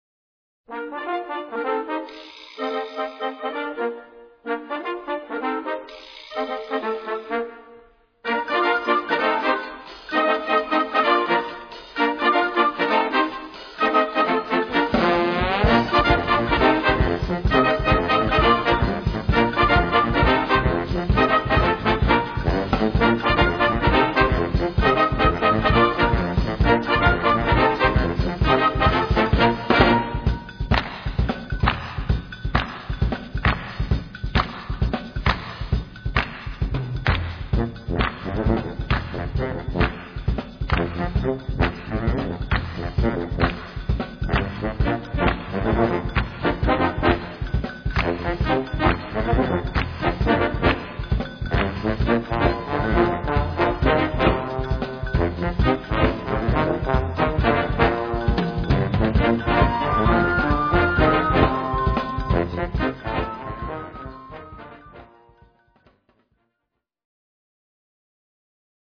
Gattung: Modernes Jugendwerk
Besetzung: Blasorchester
Man kann sie auch langsamer und somit als Samba spielen.